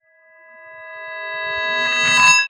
time_warp_reverse_spell_05.wav